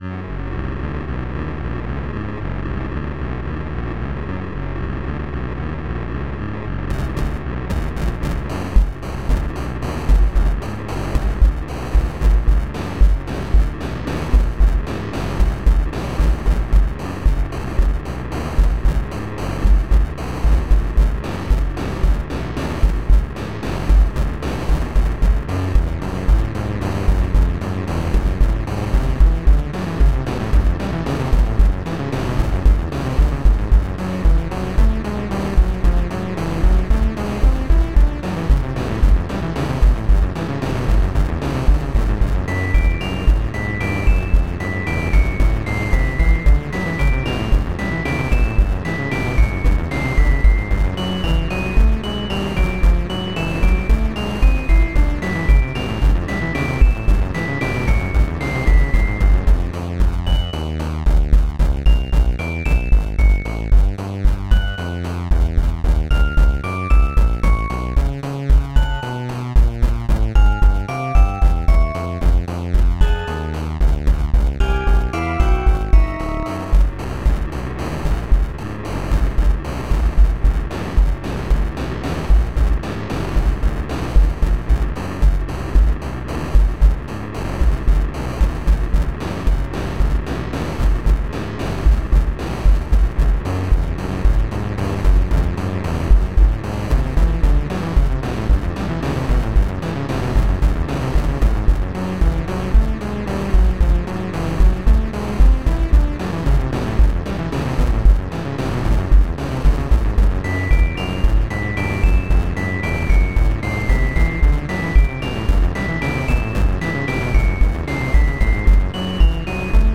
The name is quite self-explanatory; designed to capture the menacingness of the Bedrock Edition Wither.